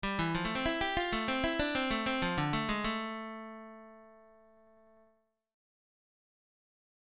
Blues lick > Lick 5